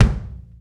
• Rich Mid-Range Kick Drum Single Hit B Key 514.wav
Royality free kick drum sample tuned to the B note. Loudest frequency: 451Hz
rich-mid-range-kick-drum-single-hit-b-key-514-Xhh.wav